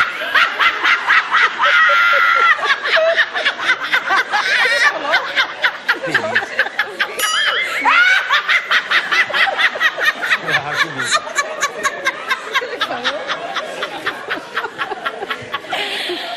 Risada Christina Rocha
Ataque de risos da apresentadora do SBT Christina Rocha. Ela começou a gargalhar com o sorriso imperfeito de um participante do programa dela.
risada-christina-rocha.mp3